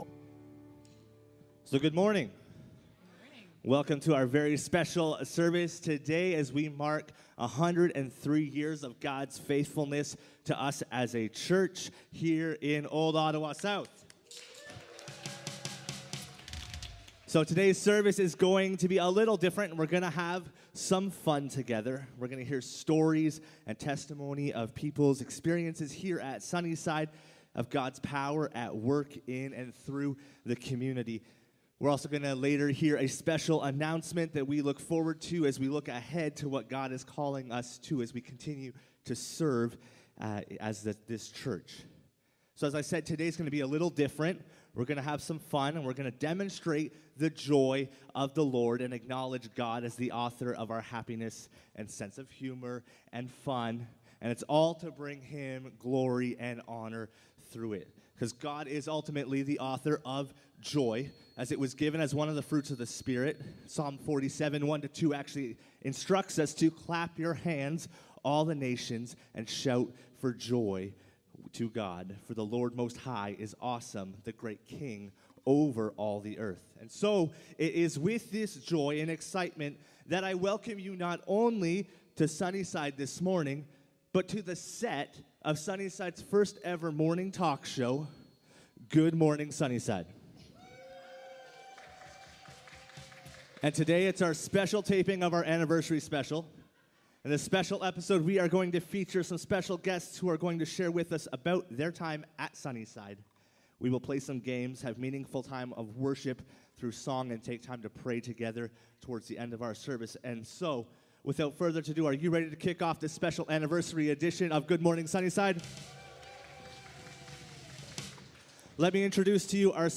Family Service